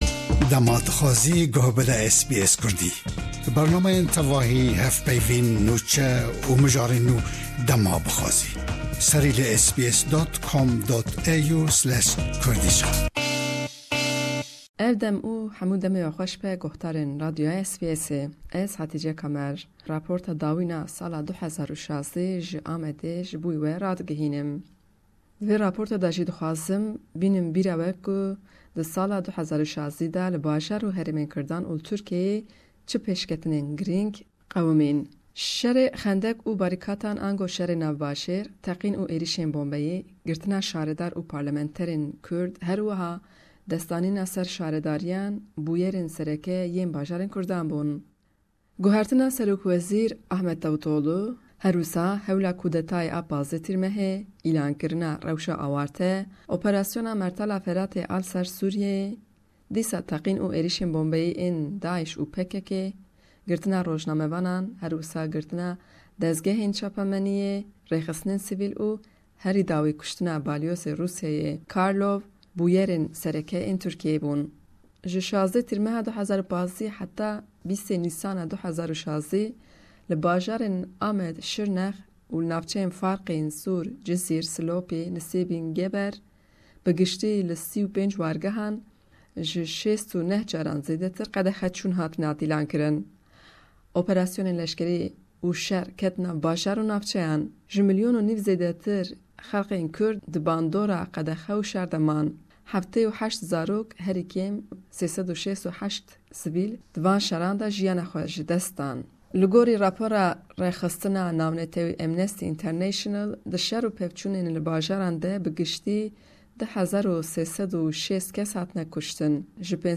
Rapora ji Diyarbekir: Rûdanên sal 2016